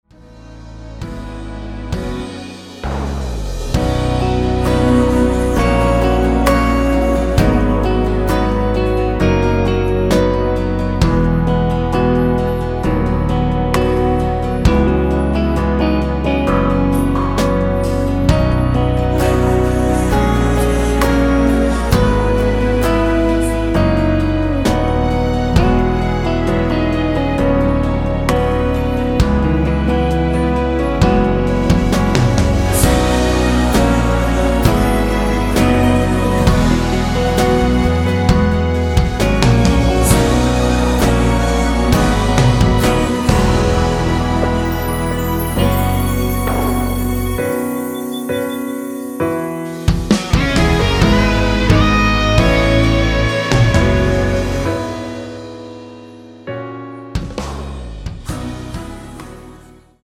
원키에서(-2)내린 코러스 포함된 MR입니다.(미리듣기 확인)
앞부분30초, 뒷부분30초씩 편집해서 올려 드리고 있습니다.
중간에 음이 끈어지고 다시 나오는 이유는